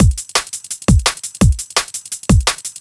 Drum N Bass 2.wav